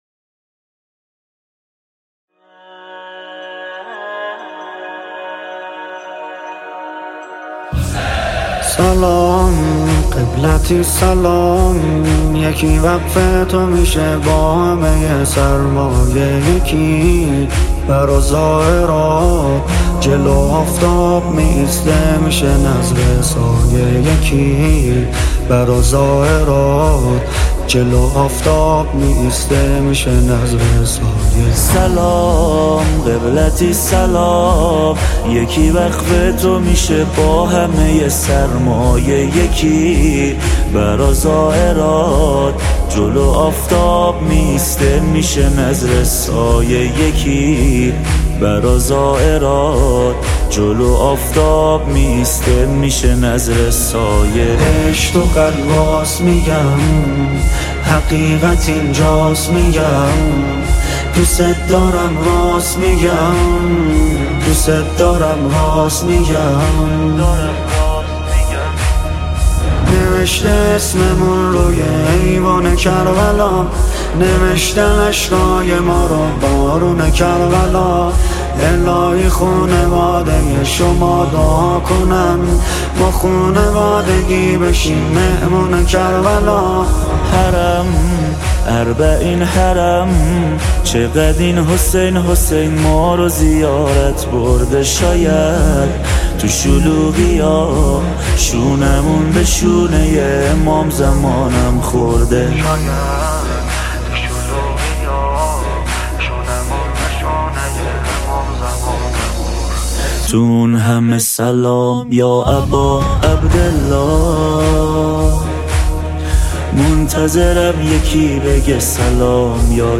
دانلود نماهنگ و مناجات دلنشین